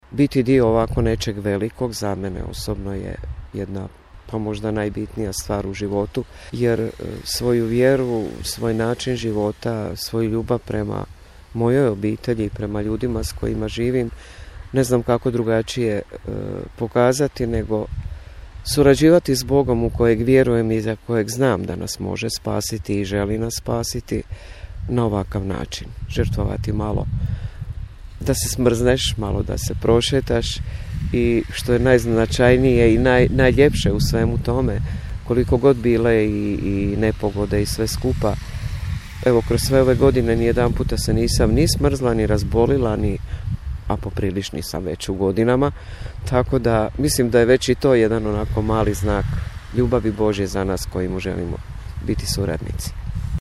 Kada se u nešto čvrsto vjeruje, nije problem izdržati niti niske temperature, kaže naša sugrađanka.
Vjera je jača od svih prolaznih nepogoda, ističe naša sugrađanka.